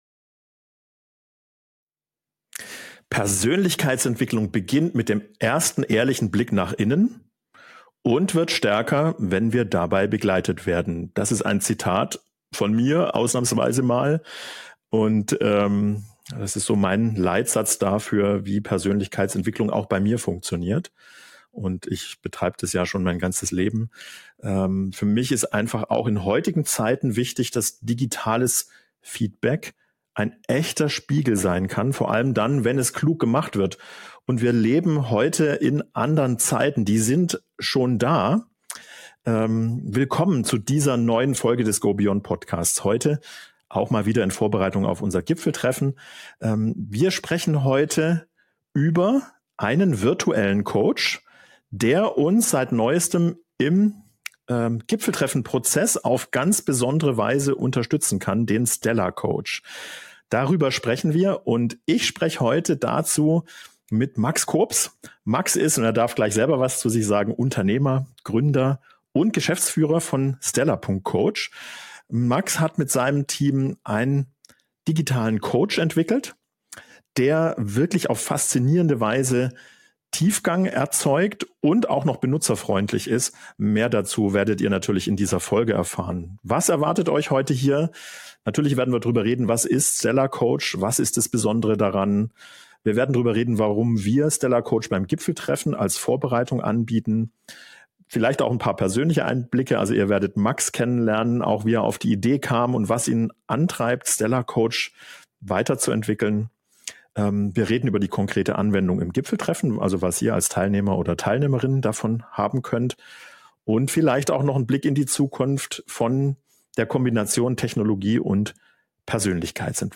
Und wie kann Technologie echte Selbstreflexion ermöglichen, ohne an Tiefe zu verlieren? Ein inspirierendes Gespräch über digitale Begleitung, innere Klarheit und die Verbindung von Coaching und Technologie.